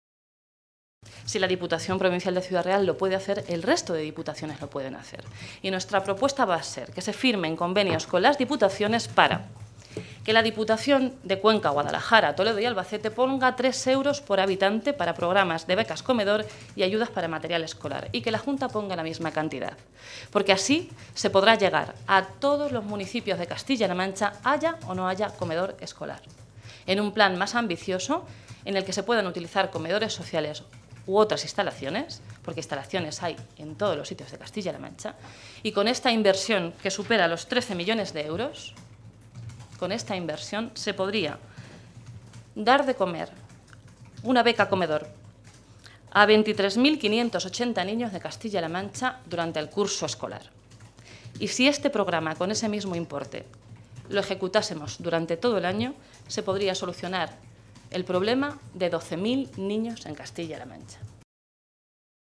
La diputada regional socialista hacía este anuncio hoy, en una comparecencia ante los medios de comunicación en Toledo, en la que recordaba que “estamos en una región en la que hay 90.000 familias con todos sus miembros en paro, en la que la tasa de paro está por encima de la media nacional y en la que la cobertura por desempleo es menor que la que hay en el resto del país”.
Cortes de audio de la rueda de prensa